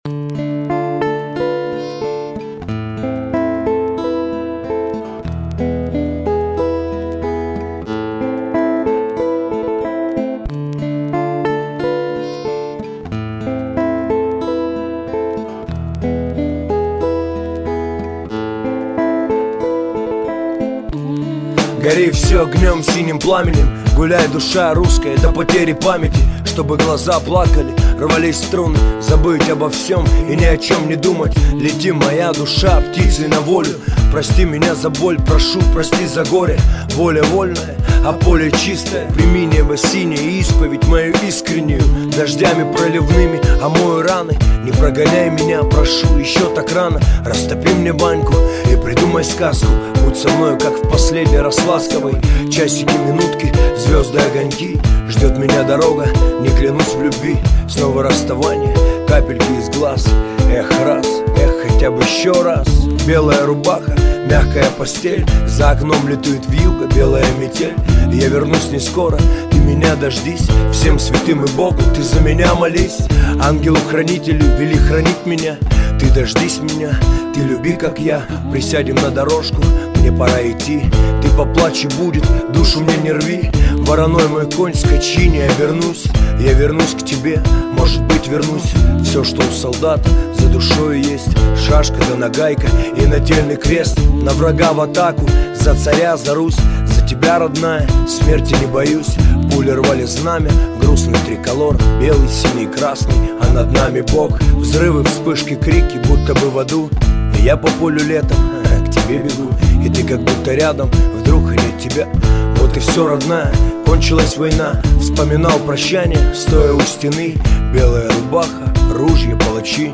2011 Жанр: Rap Страна